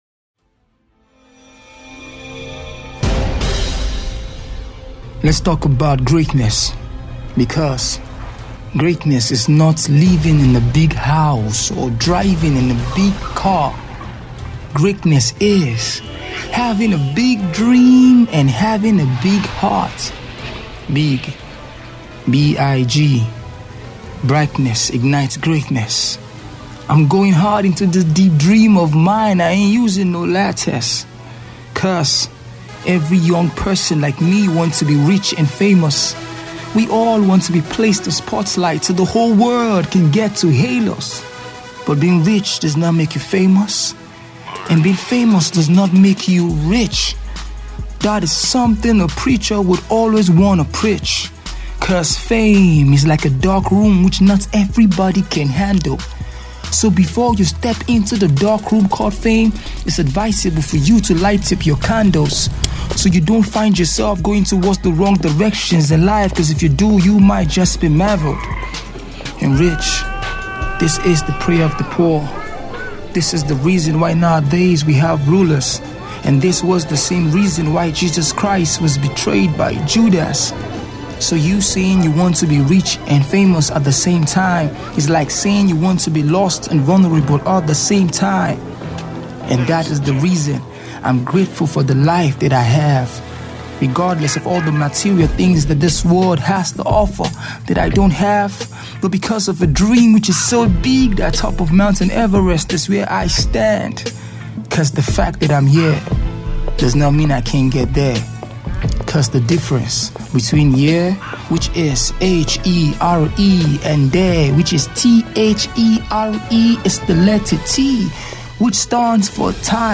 Debut Spoken Word
Spoken-word single